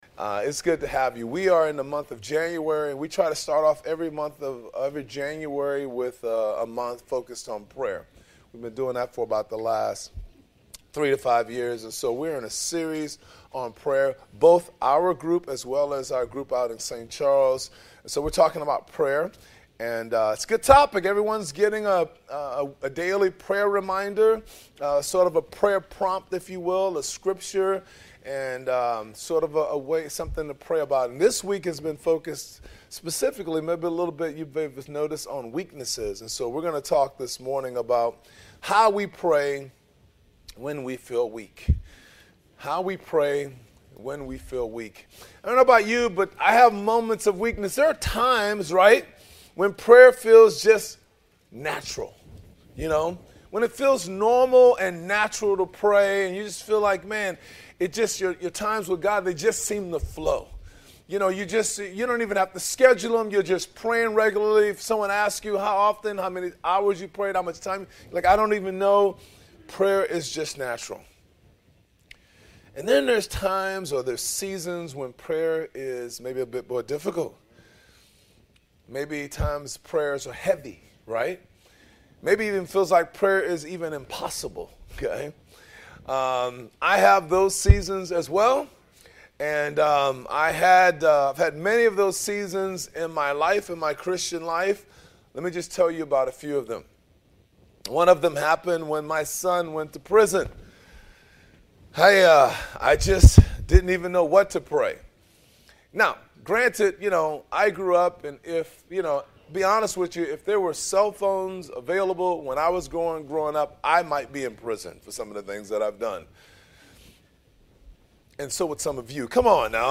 Sermons | Gateway City Church